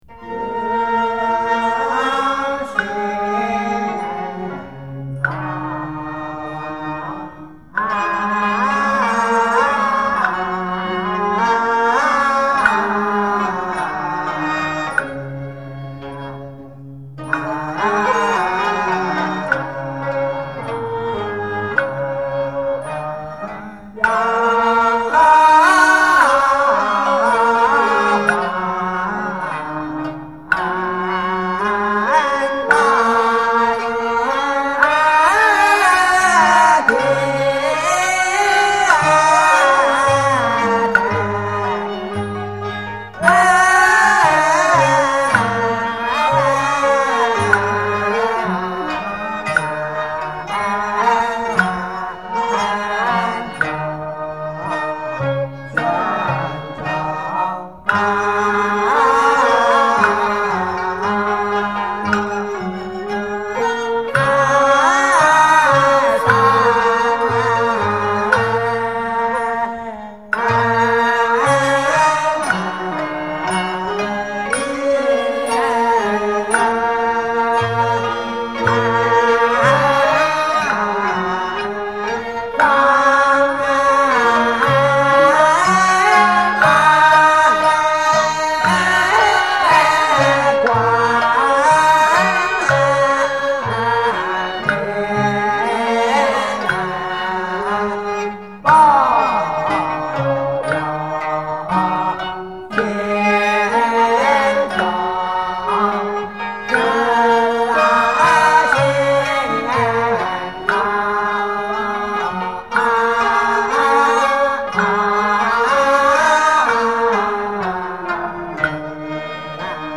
中国道教音乐-茅山道乐-早朝